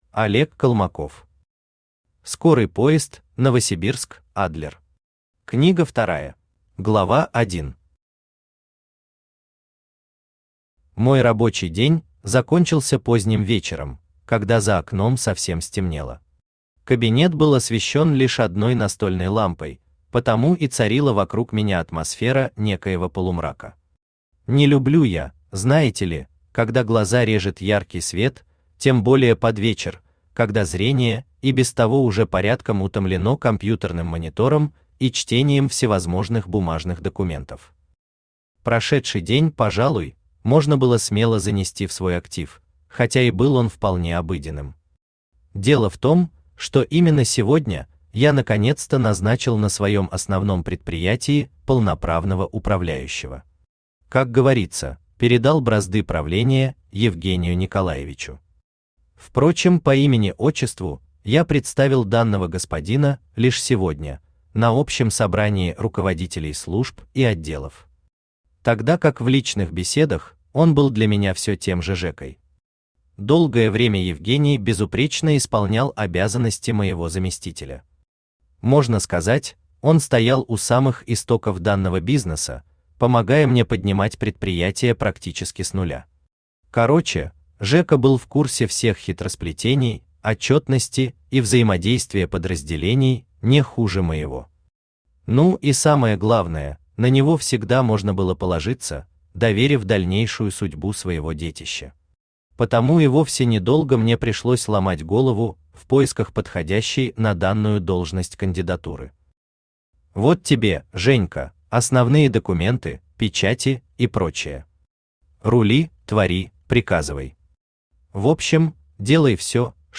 Книга вторая Автор Олег Колмаков Читает аудиокнигу Авточтец ЛитРес.